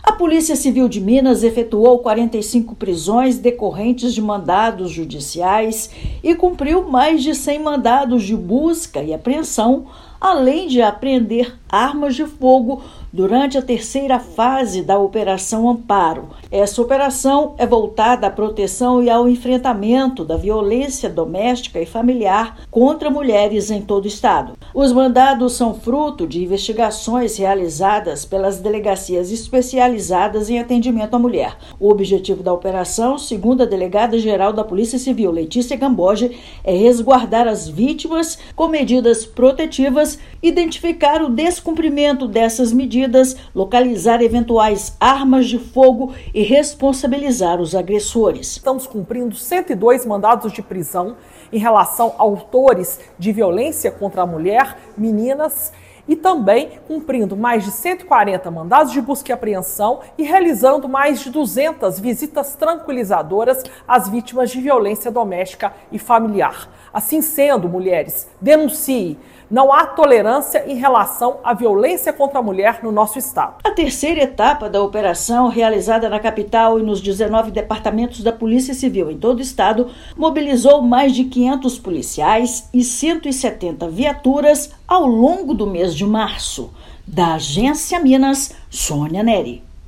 Agência Minas Gerais | [RÁDIO] Polícia Civil realiza 45 prisões em terceira fase de operação de combate à violência contra a mulher em Minas Gerais
Operação Amparo é marcada por ações repressivas e atuação preventiva no estado. Ouça matéria de rádio.